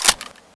m4a1_clipin.wav